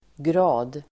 Uttal: [gra:d]